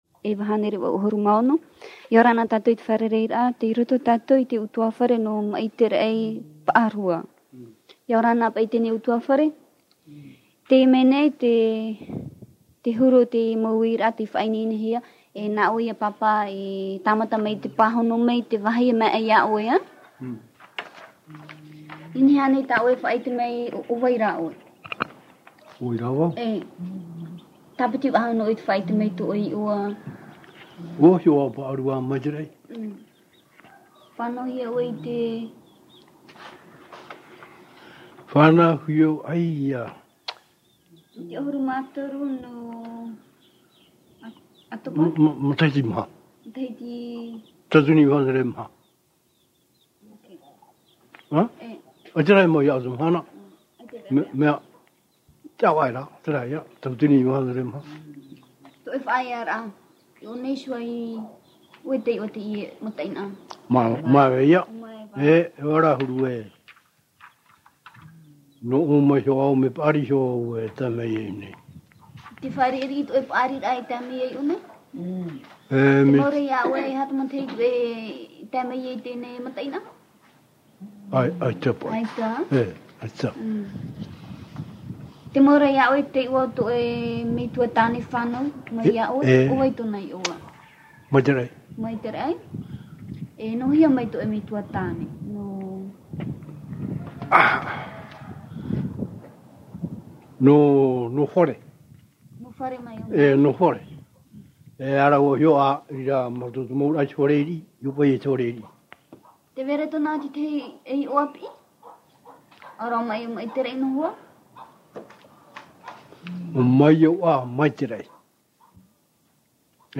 Te ta’ata fa’ati’a